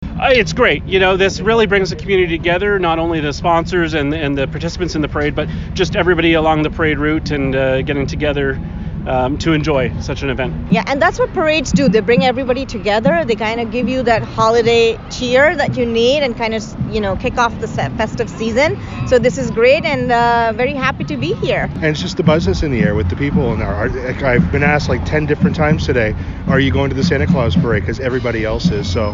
Others touched on the buzz that was created leading up to the event.